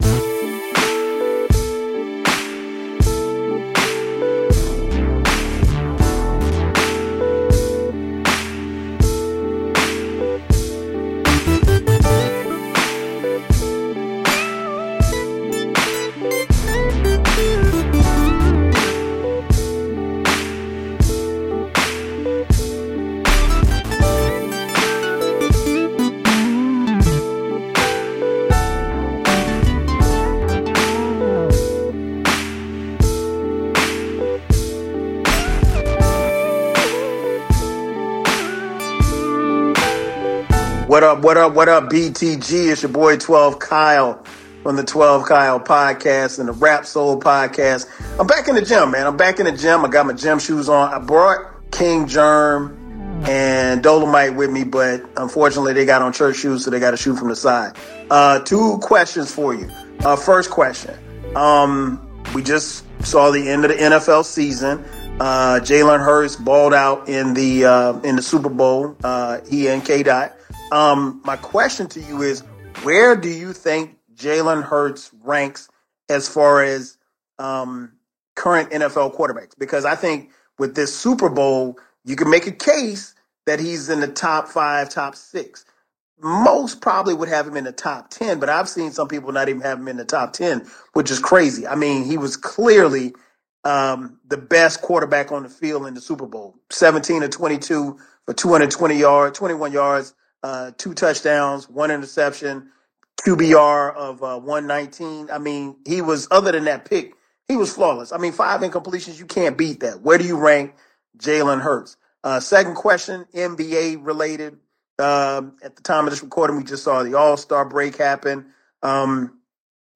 1 When Can We Kill "These People" 25:37 Play Pause 25d ago 25:37 Play Pause Afspil senere Afspil senere Lister Like Liked 25:37 In this episode of BTG SQUAD, we'll watch mind-blowing moment from a Charlie Kirk speaking event. A man in the audience makes a statement so outrageous, it left everyone speechless.